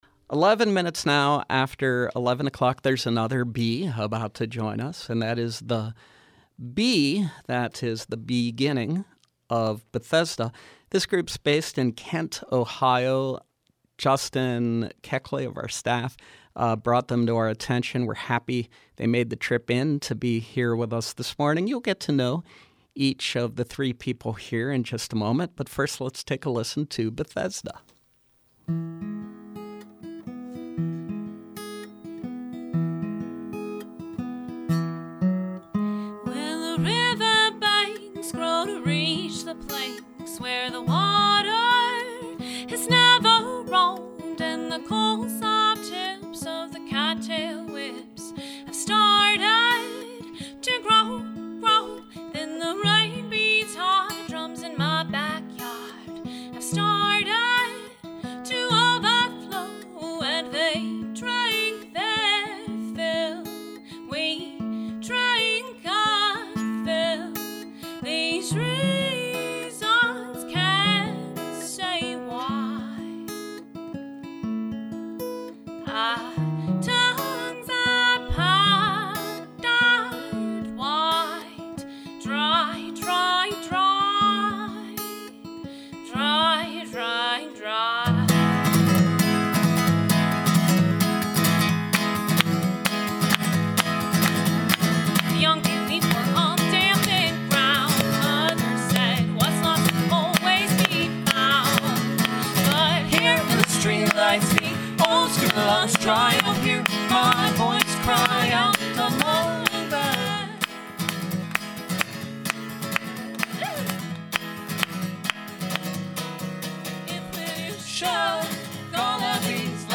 indie-folk
performs live